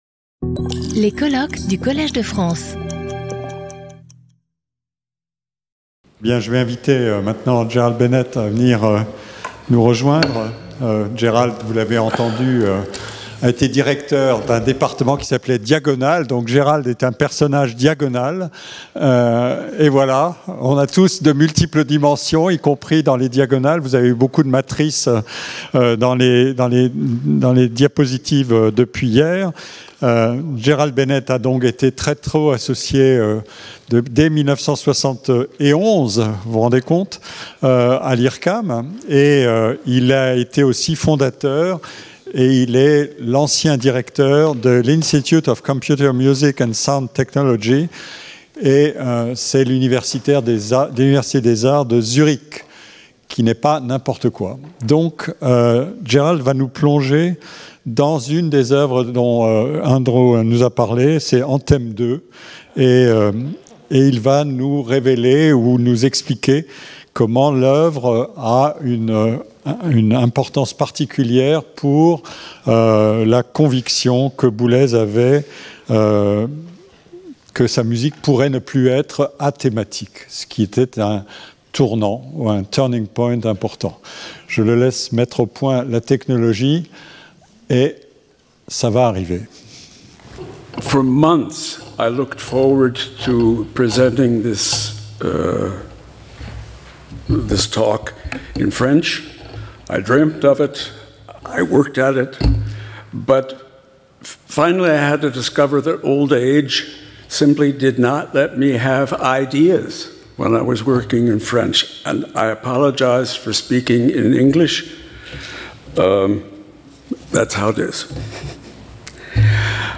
Sauter le player vidéo Youtube Écouter l'audio Télécharger l'audio Lecture audio Conférence en anglais.